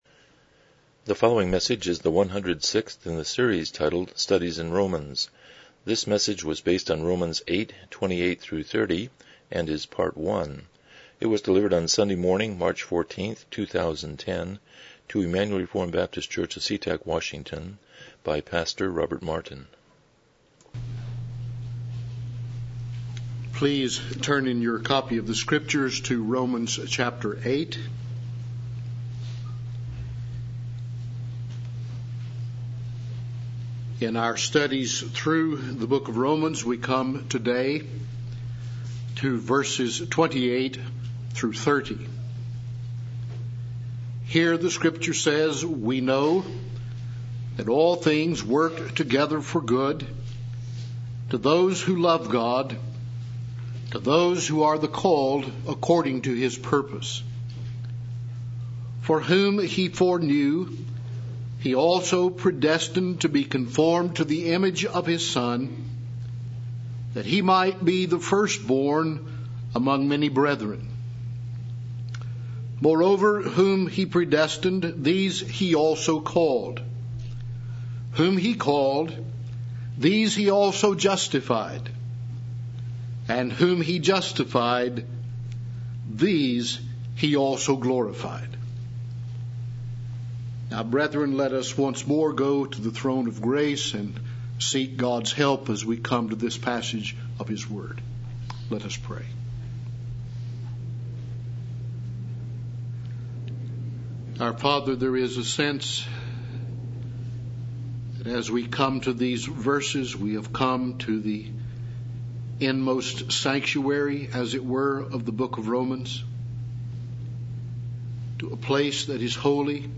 Passage: Romans 8:28-30 Service Type: Morning Worship